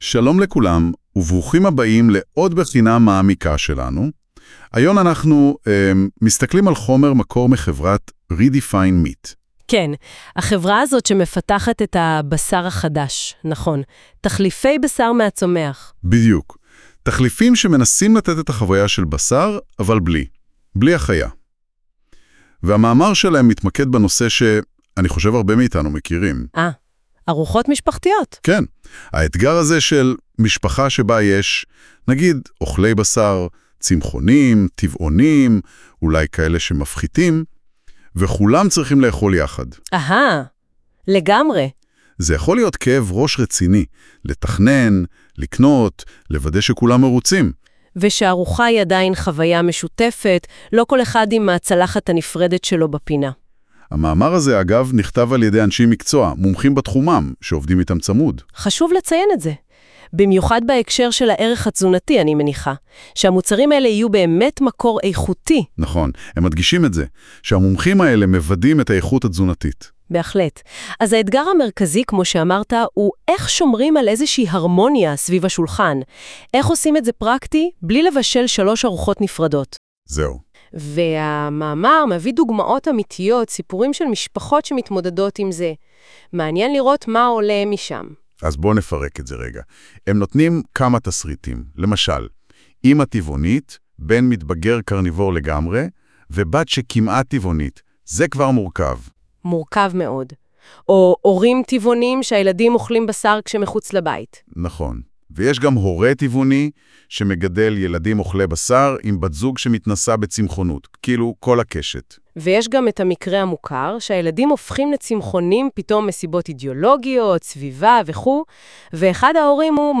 הכנו עבורכם באמצעות כליי AI מבית גוגל, פודקאסט קצר שיאפשר לכם להאזין לתוכן בנוסף לקריאה: